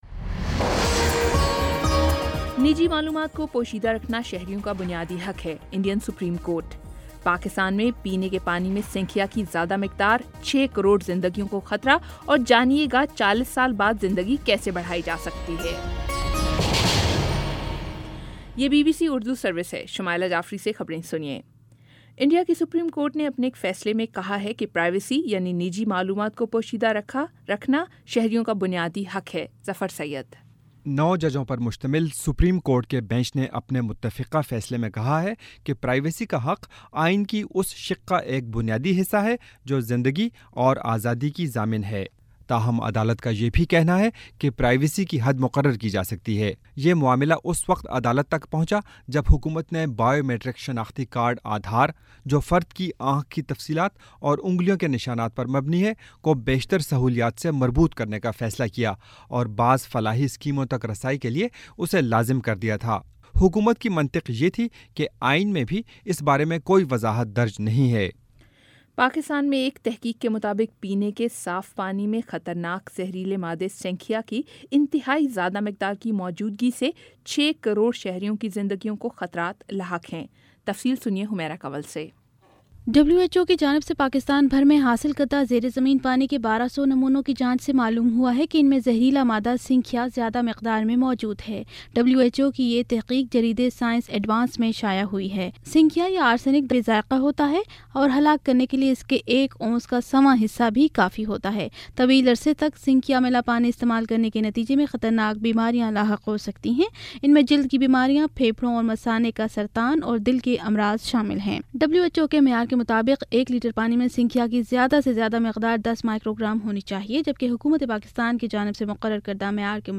اگست 24 : شام پانچ بجے کا نیوز بُلیٹن